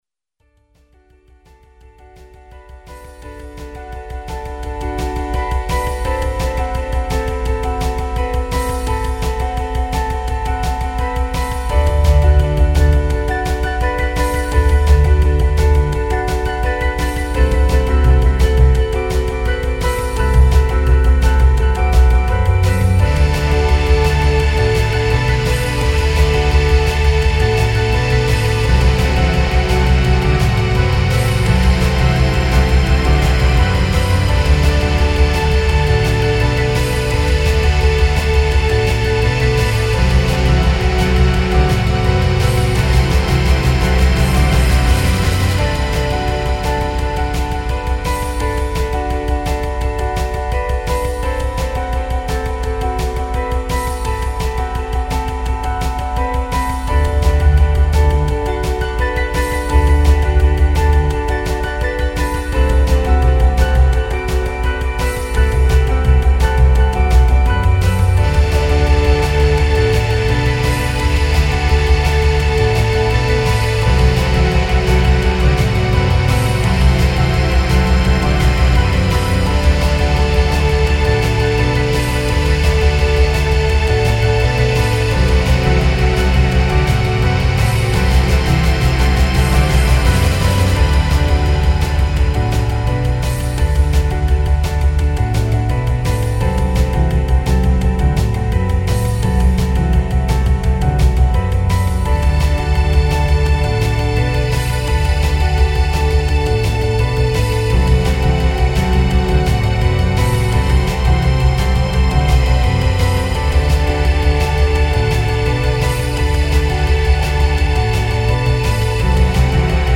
Melodic Piano Rock